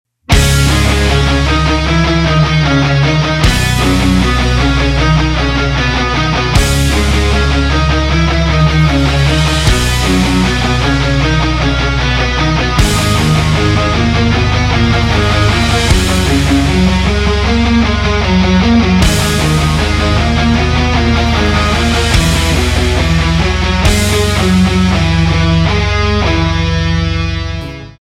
Крутой рок рингтон